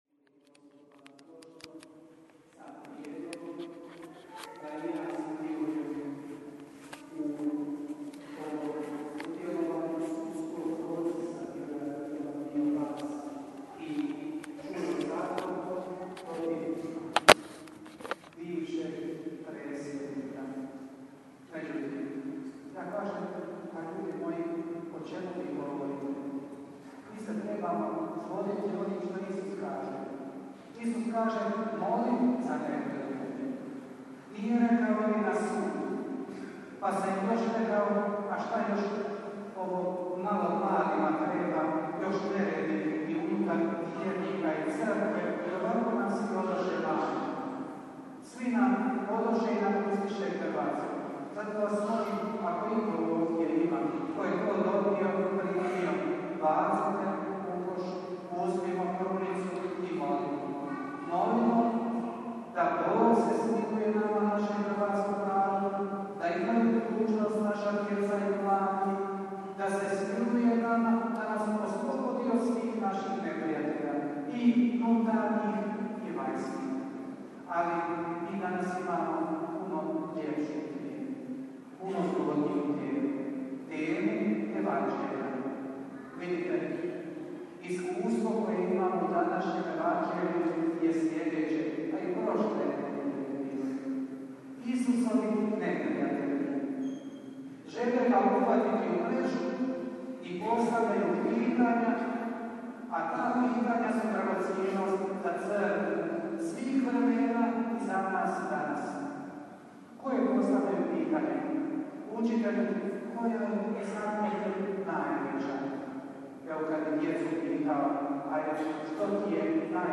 PROPOVJED:
TUMAČENJE EVANĐELJA